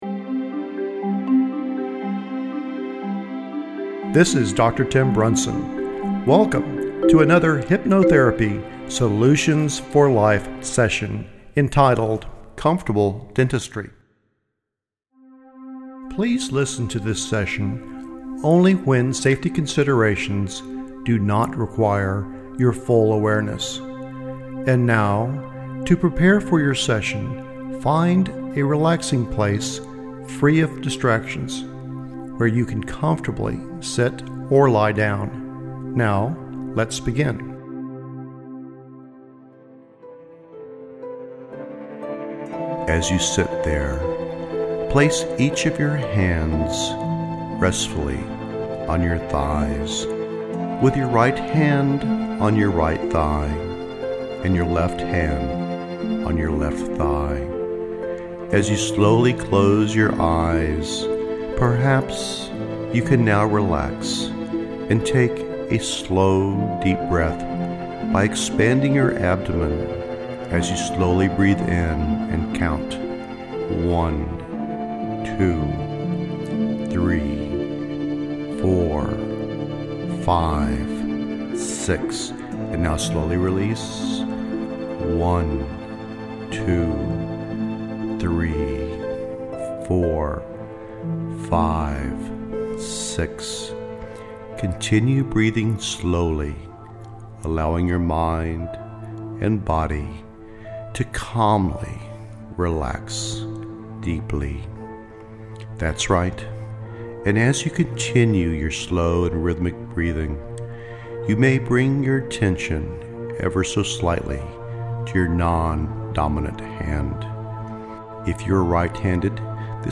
We also offer a self-hypnosis/biofeedback audio which will help relieve this fear and anxiety.